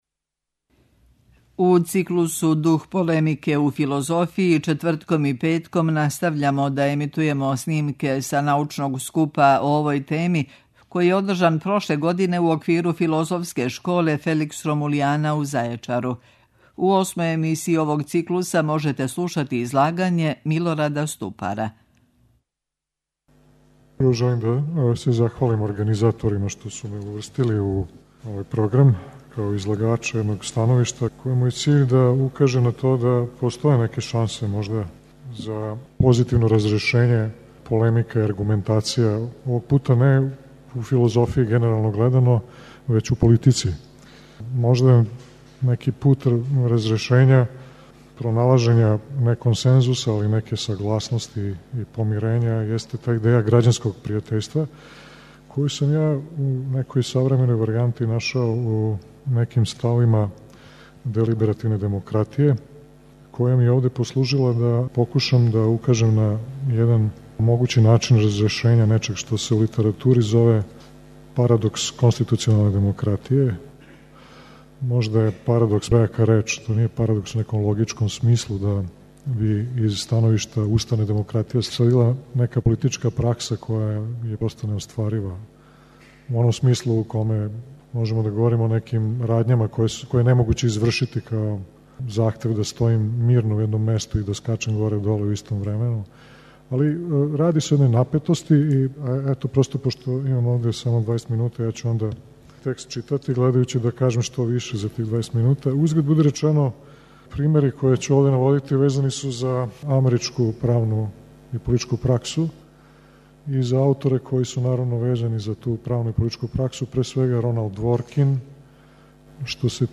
У циклусу ДУХ ПОЛЕМИКЕ У ФИЛОЗОФИЈИ четвртком и петком емитујемо снимке са научног скупа о овој теми, који је одржан прошле године у оквиру Филозофске школе Felix Romuliana у Зајечару.
Научни скупови
Прошлог лета у Зајечару одржана је шеснаеста Филозофска школа Felix Romuliana, научни скуп са традицијом дугом 22 године.